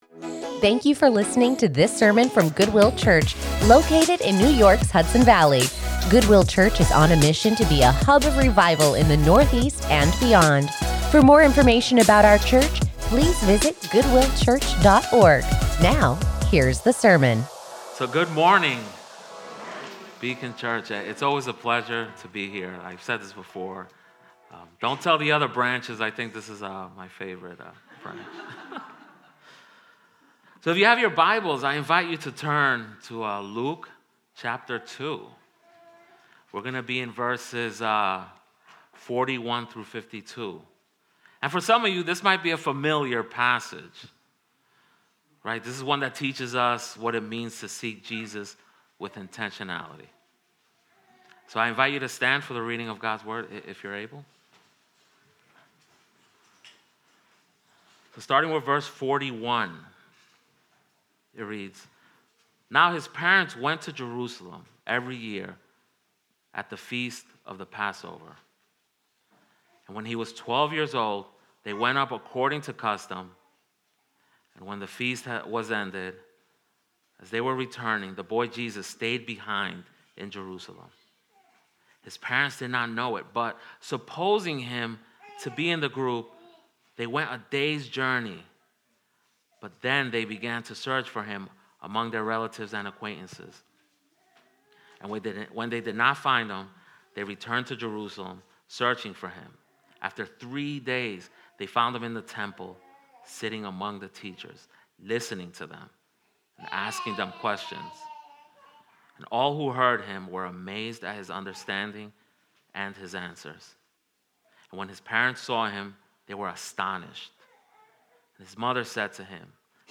Join us in worship and in the study of God's Word as we take a quick break from our sermon series with this sermon